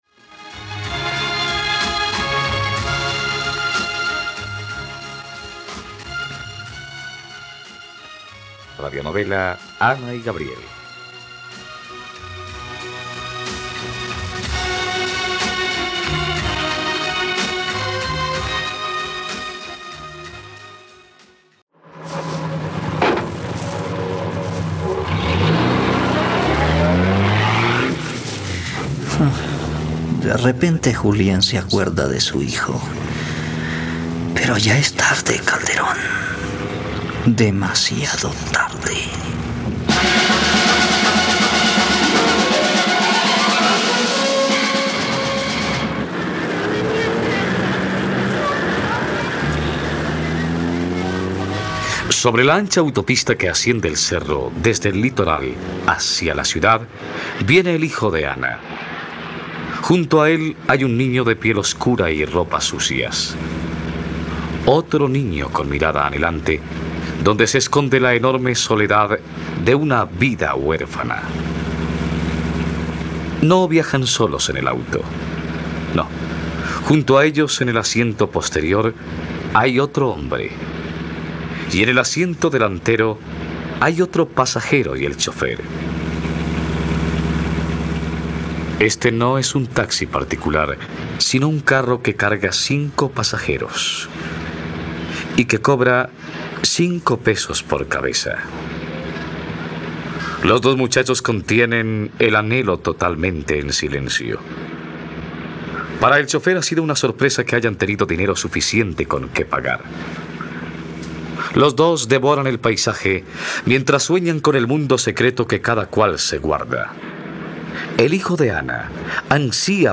..Radionovela. Escucha ahora el capítulo 91 de la historia de amor de Ana y Gabriel en la plataforma de streaming de los colombianos: RTVCPlay.